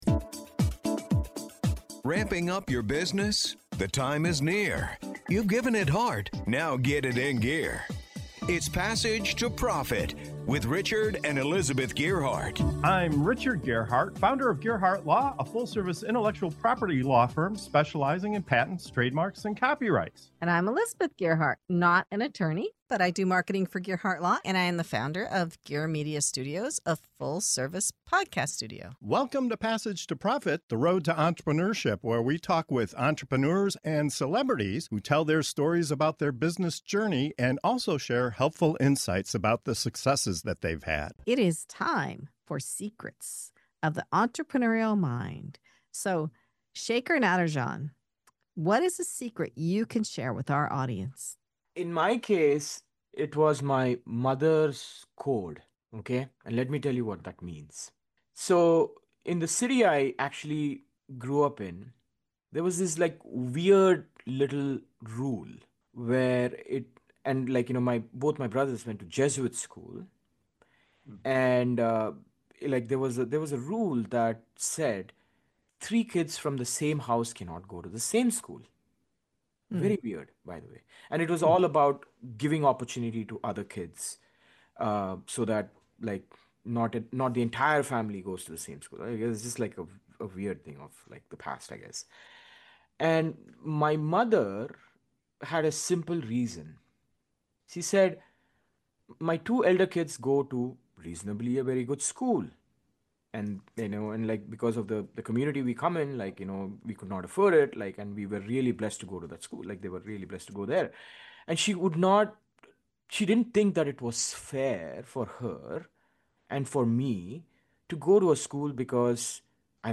In this segment of of "Secrets of the Entrepreneurial Mind" on Passage to Profit Show, our guests open up about the principles and practices that fuel their success—from the relentless persistence of outlasting obstacles, to leading with passion, to making tough choices by always doing the right thing. You’ll hear powerful personal stories, timeless lessons in integrity, and a surprising modern twist on why mastering AI tools like ChatGPT may be one of the smartest entrepreneurial skills you can build today.